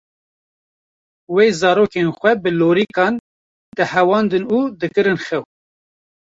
Vrouwelijk
/xɛw/